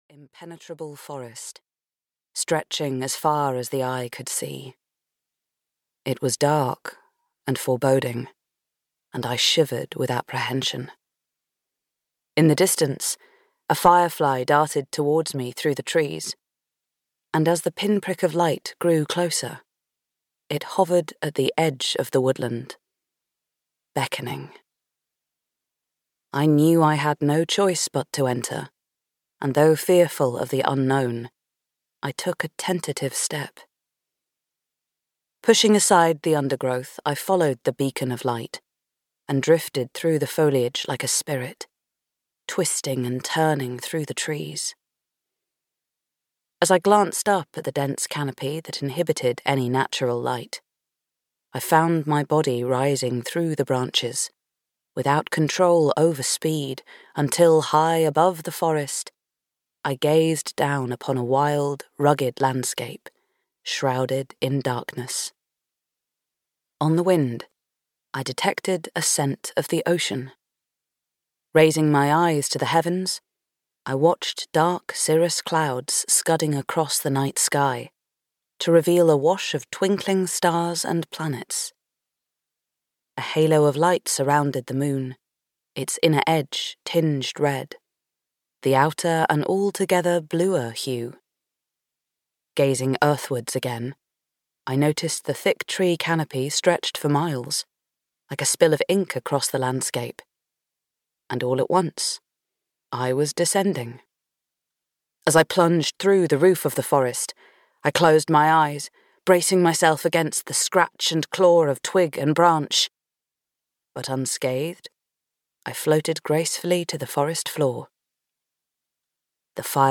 Beneath Cornish Skies (EN) audiokniha
Audiobook Beneath Cornish Skies written by Kate Ryder.
Ukázka z knihy